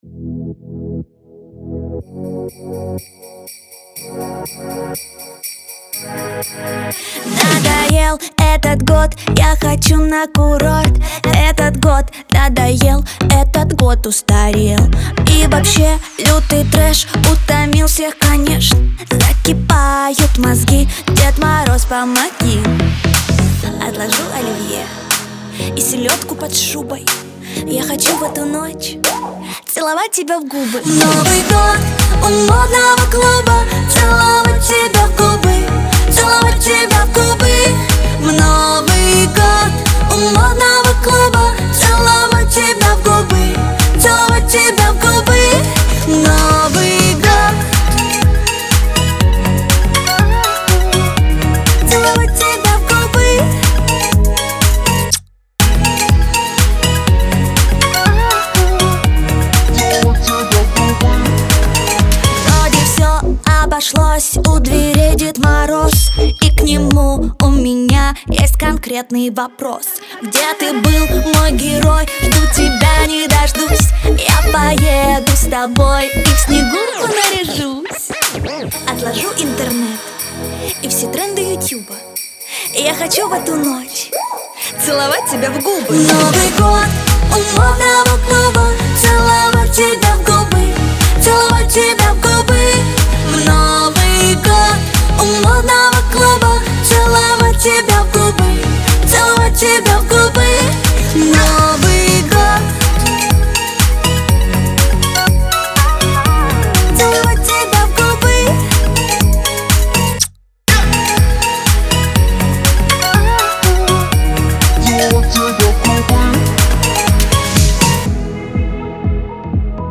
это яркий и запоминающийся трек в жанре поп